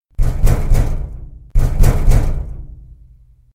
Sound Buttons: Sound Buttons View : Pounding On Metal Door
pounding-metal-door.mp3